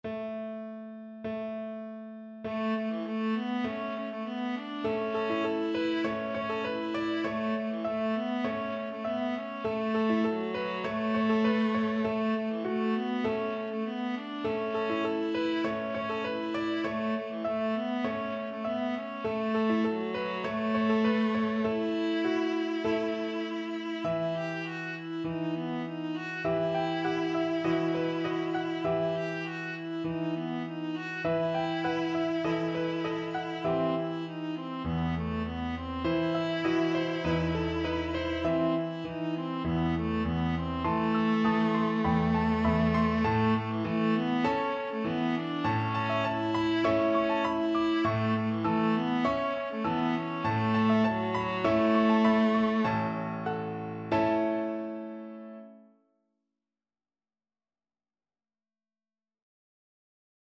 Viola version
Viola
Traditional Music of unknown author.
A major (Sounding Pitch) (View more A major Music for Viola )
With energy
Traditional (View more Traditional Viola Music)